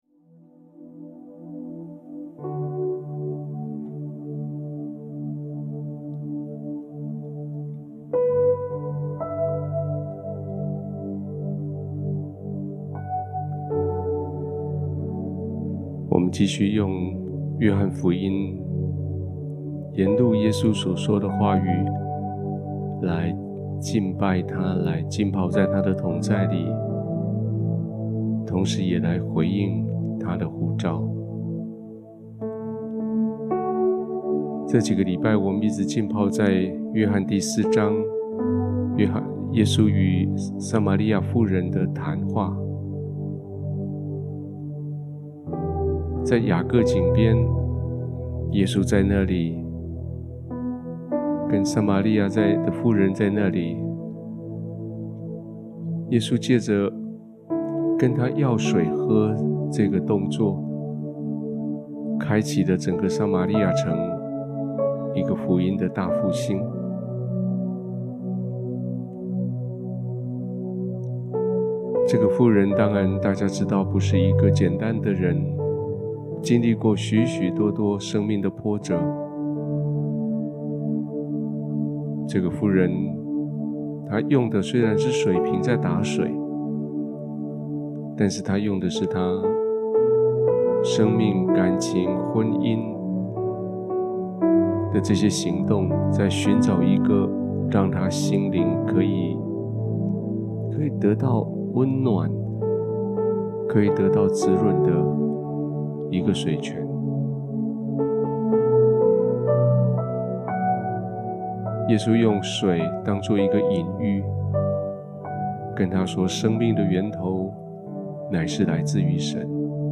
今天，牧师依然用约翰福音第四章 1-30节，撒马利亚妇人的心来浸泡祷告。带领，浸泡，思想祷告。 你可以随着音频一起回应唱诗和浸泡，选择一个的地方，用你最舒适的方式坐下。